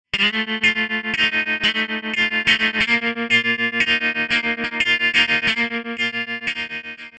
tremolo.mp3